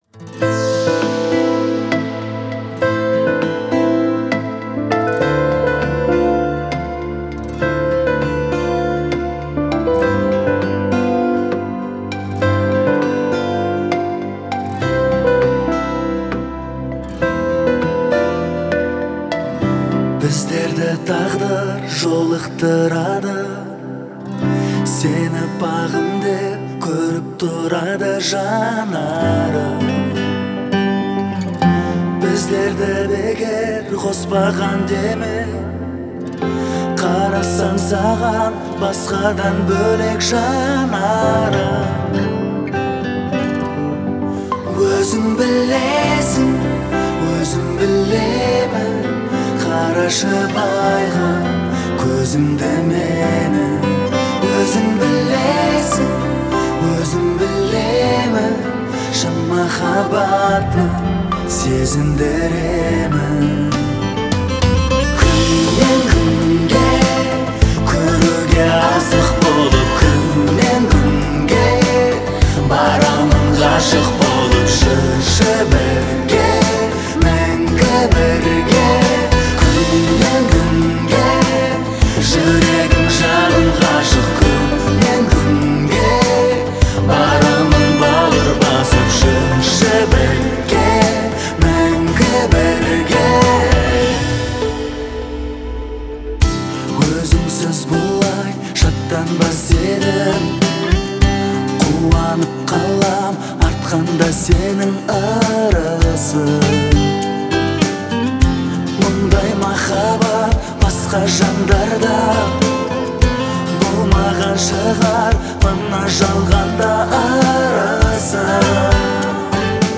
это трек в жанре казахской поп-музыки
Звучание песни отличается мелодичностью и душевностью